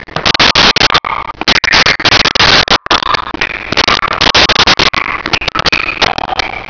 Sfx Amb Drip Echo Loop
sfx_amb_drip_echo_loop.wav